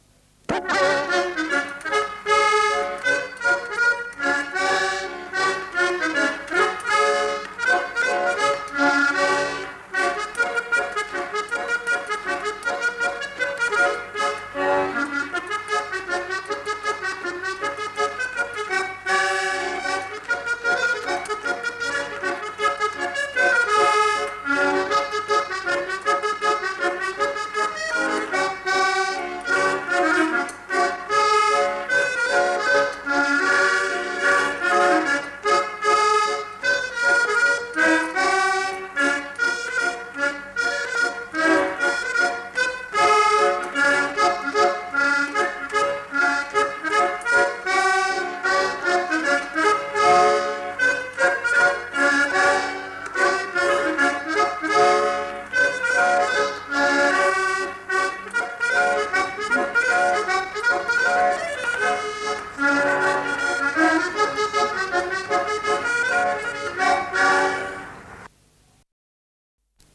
Aire culturelle : Cabardès
Lieu : Mas-Cabardès
Genre : morceau instrumental
Instrument de musique : accordéon diatonique
Danse : varsovienne
Notes consultables : Le joueur d'accordéon n'est pas identifié.